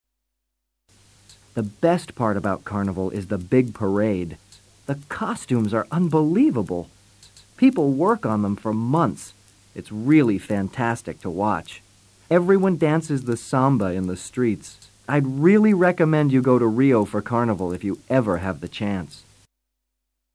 Listen to an American tourist explaining about famous Carnival